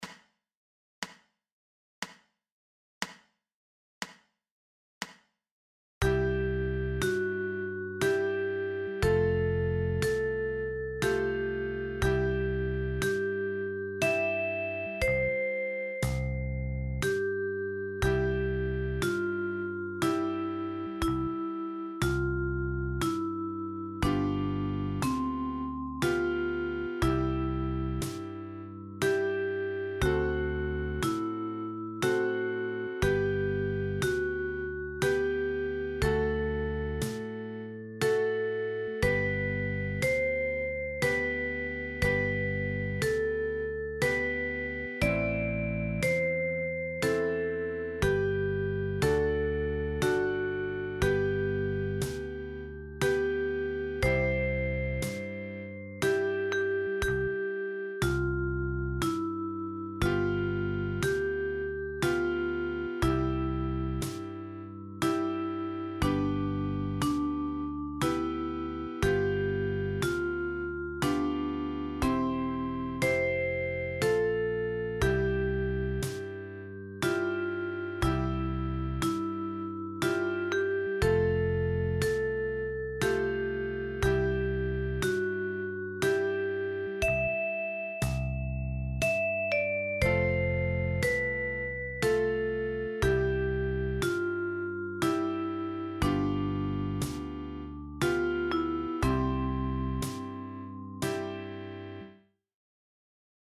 Liederbuch (in deutsch & english) mit 25 Songs von Stephen C. Foster für die Okarina mit 6 Löchern.
Alle Lieder wurden vertont, die Sounds sind online in einem Player mit regelbarer Abspielgeschwindigkeit verfügbar.